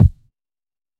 平手打ち